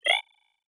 Holographic UI Sounds 103.wav